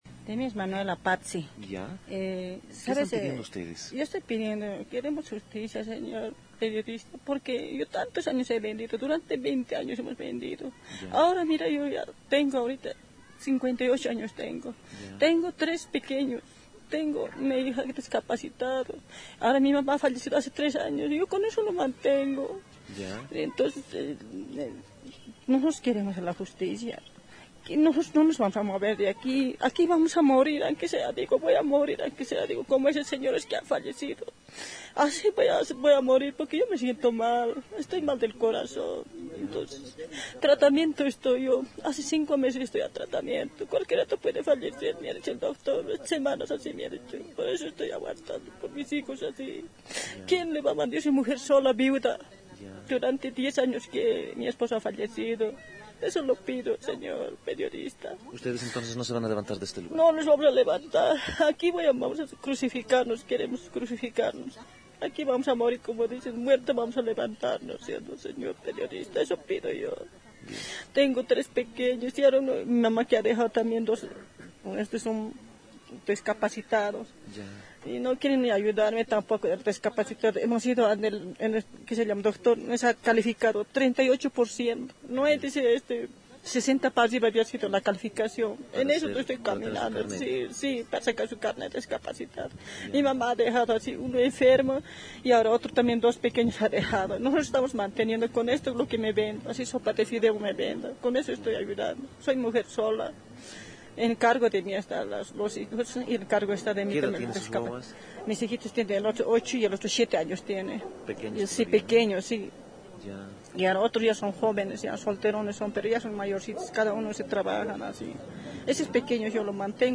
testimonio-vendedoras.mp3